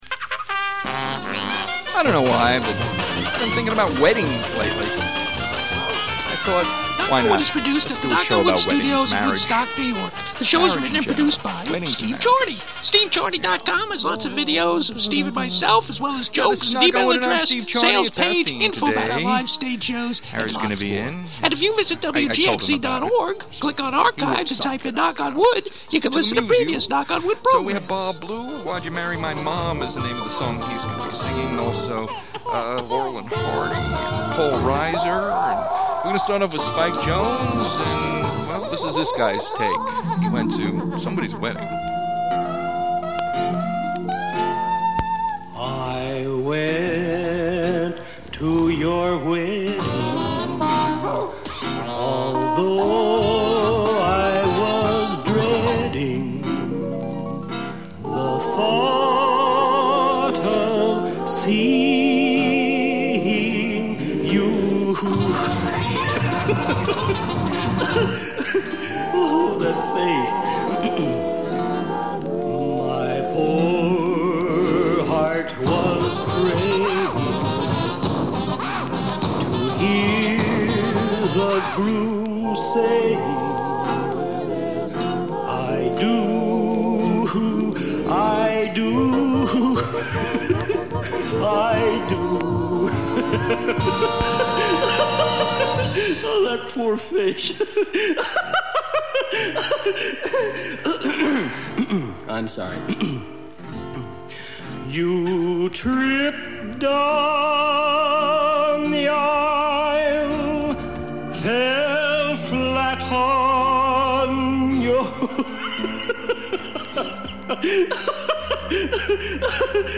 Comedy Show